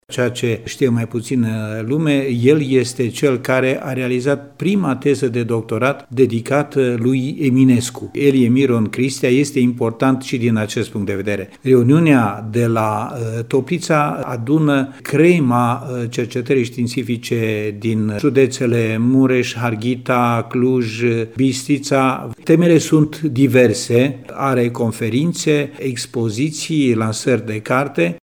Unul dintre organizatori este directorul Direcției de Cultură Mureș, Nicolae Băciuț: